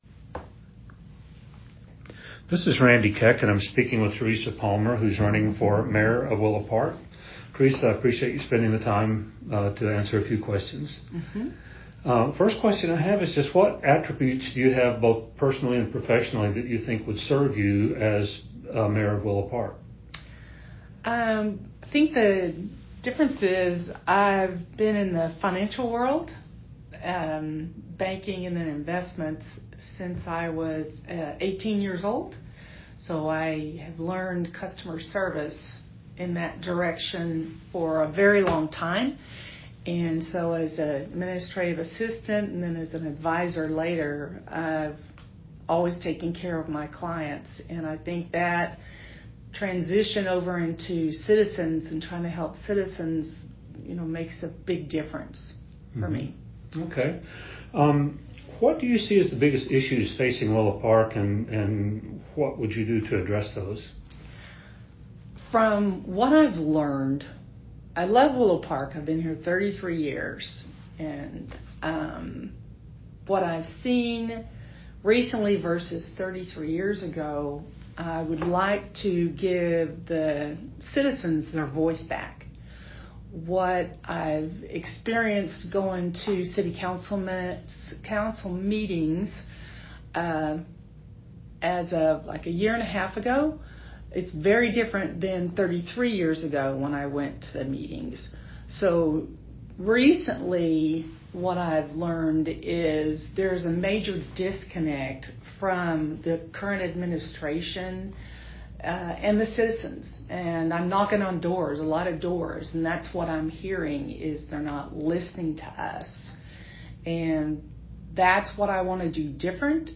VOTE TODAY: Mayor Candidates Speak.
If you haven't decided who to choose for a new mayor, we have interviews with four of those candidates